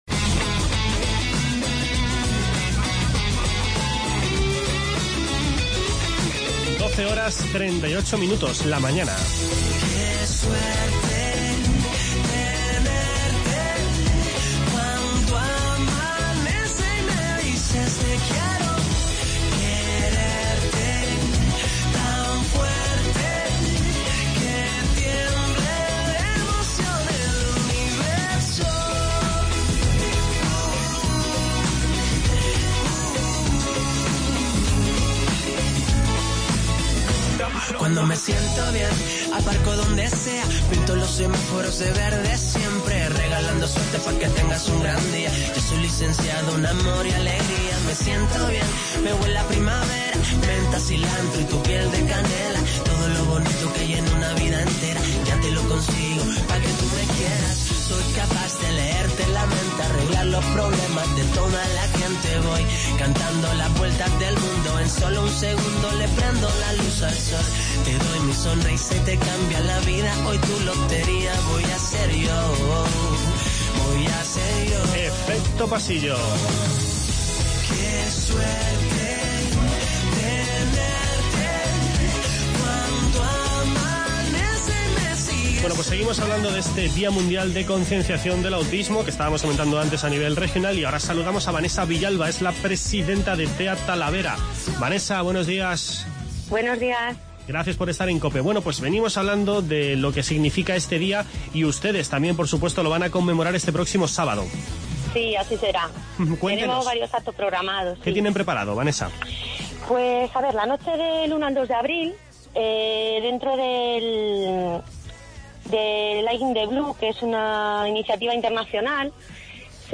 A continuación, charlamos con Carlos Goñi, vocalista de Revólver, que vuelve este sábado a Toledo con "Babilonia, al Natural".